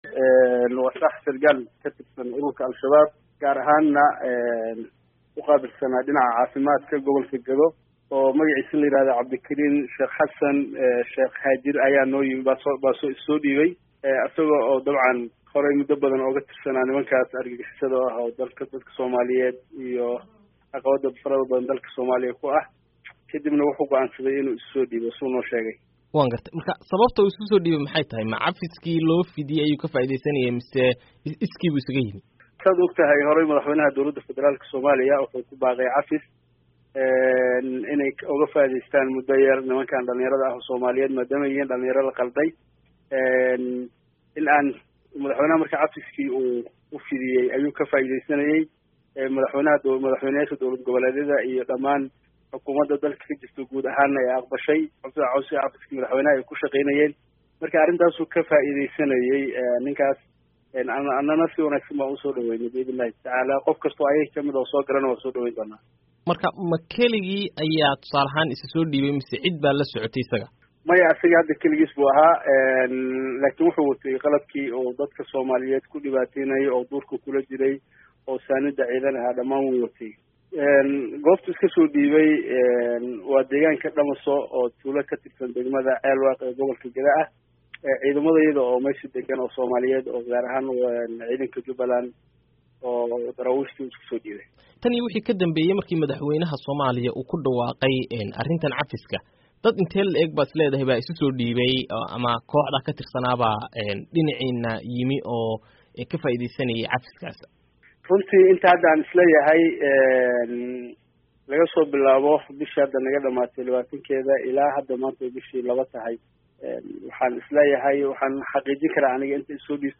Halkan Hoose ka Dhagayso Wareysiga Wasiirka Amniga